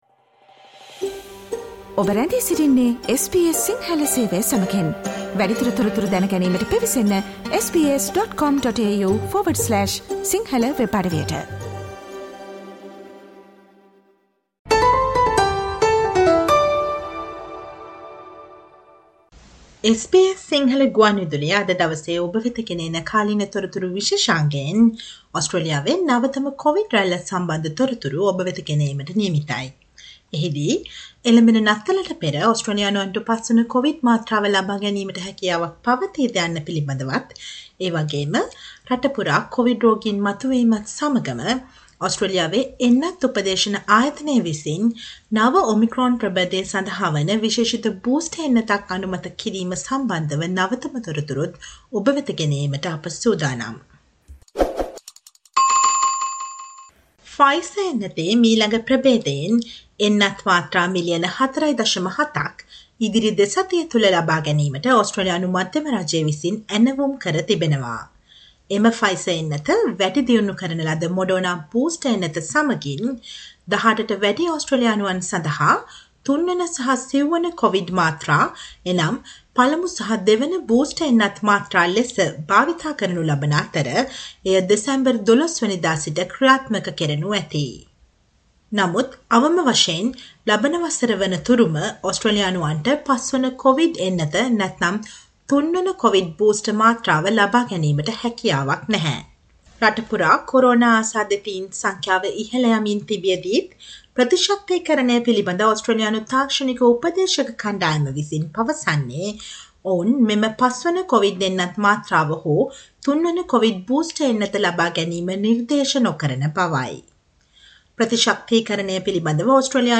Today - 17 November, SBS Sinhala Radio current Affair Feature on the new covid wave across Australia and a new booster shot to combat the virus